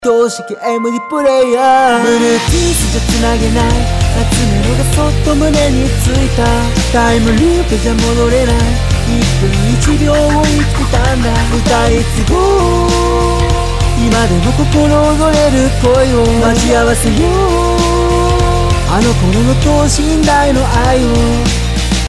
ボーカルミックスなし.mp3